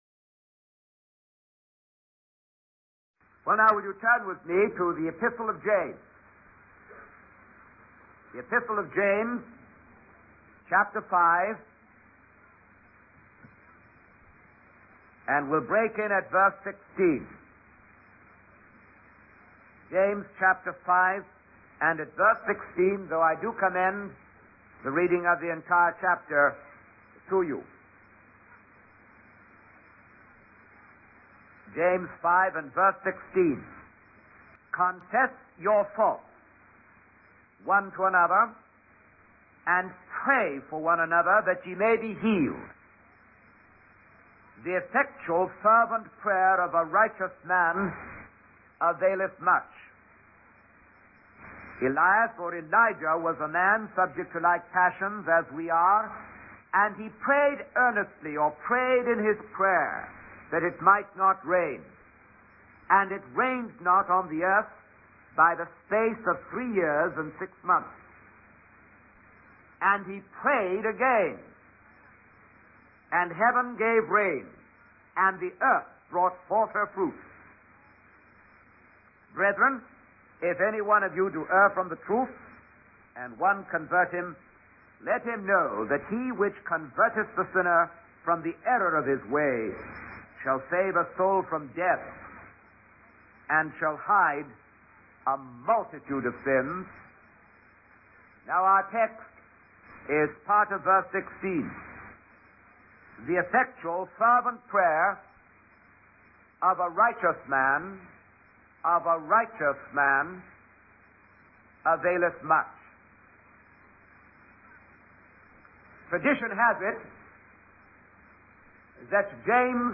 In this sermon, the speaker begins by mentioning Vietnam and encourages the audience to invite their friends to the upcoming ministry sessions. The speaker then shares a story about four men on a ship who witnessed sin and wickedness among the crew.